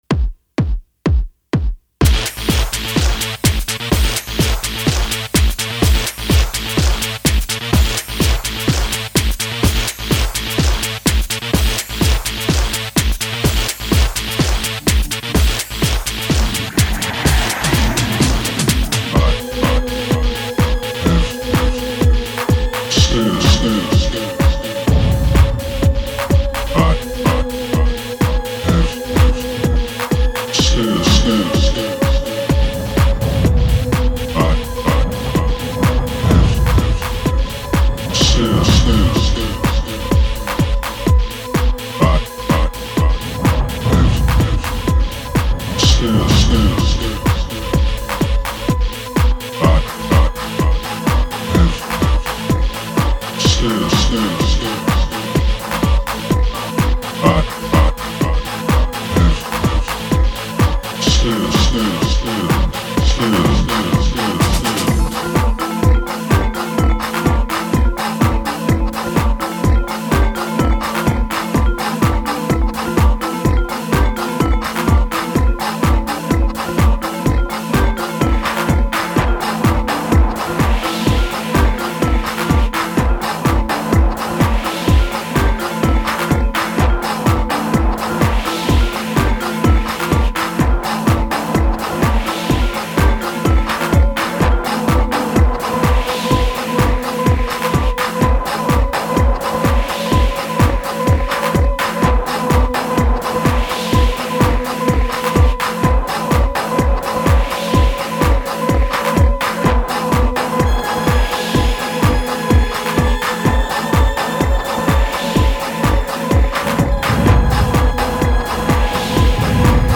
they are mixed to flow together.